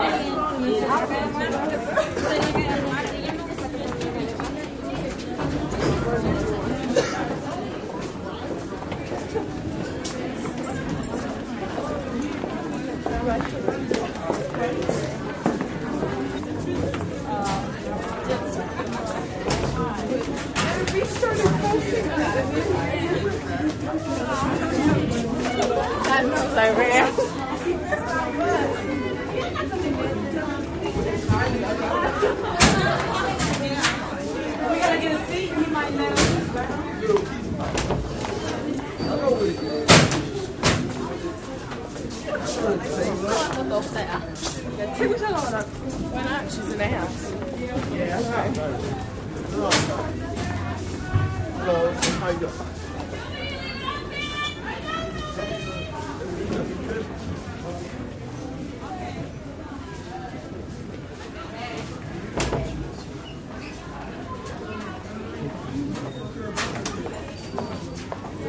School Hall Crowded With Lockers